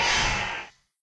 damage3.ogg